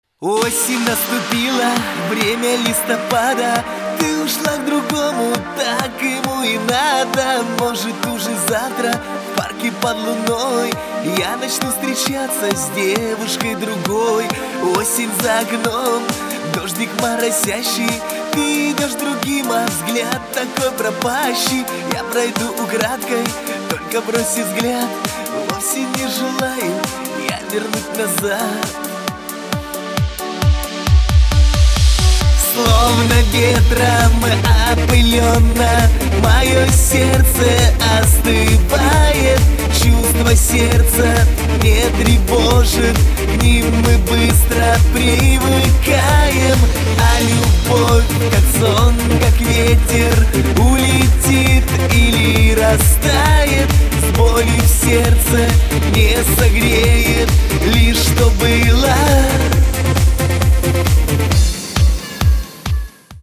• Качество: 256, Stereo
мужской вокал
русский шансон